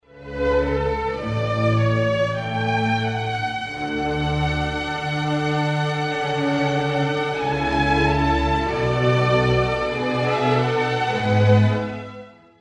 light